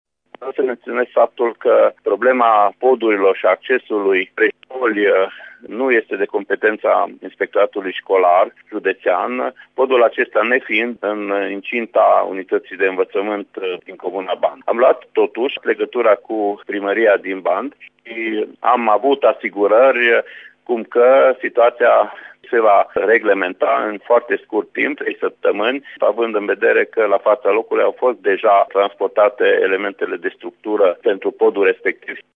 Inspectorul şcolar general al judeţului Mureş, Ştefan Someşan: